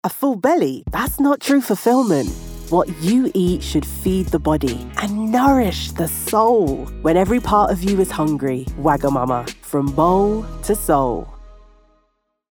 Female
Urban
Smooth Restaurant Advert
All our voice actors have professional broadcast quality recording studios.